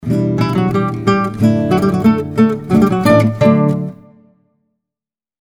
äänilogo nylonkielinen